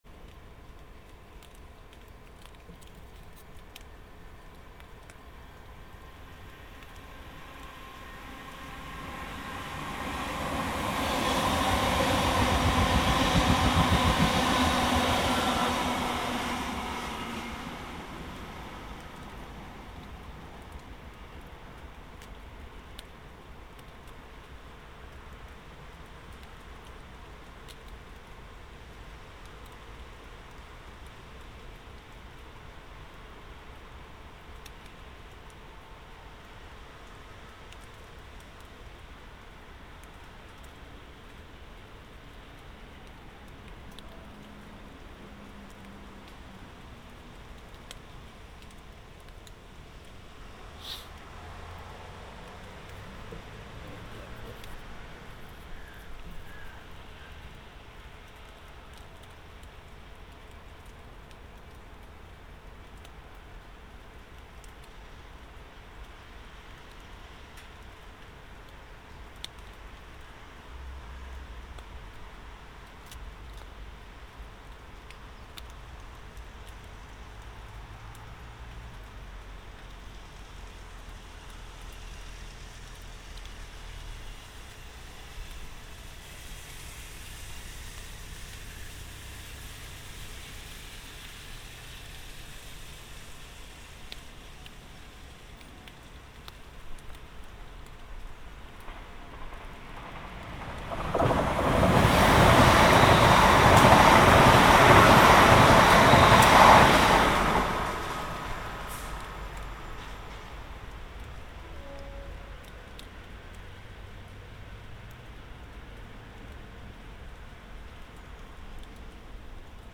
Trains passing Manor Park
Tags: Sound Map in London London sounds UK Sounds in London London